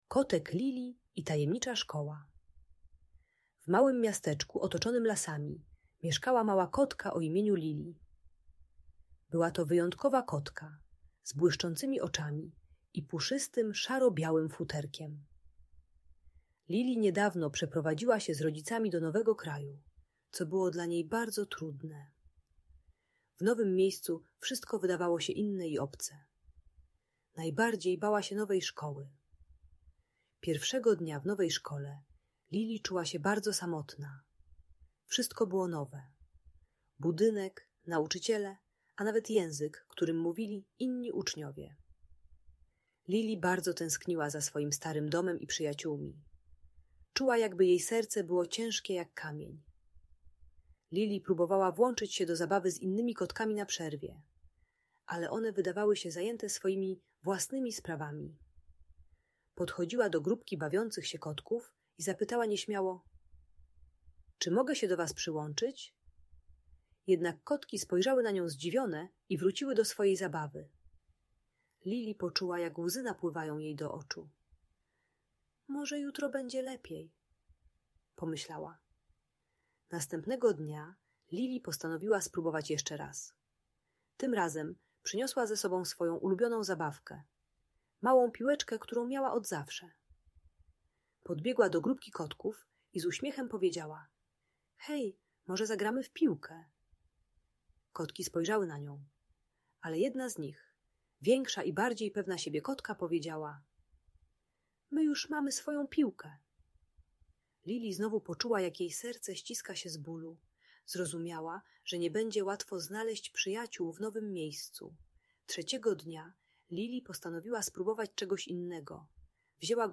Audiobajka o lęku przed odrzuceniem i samotności w nowej szkole.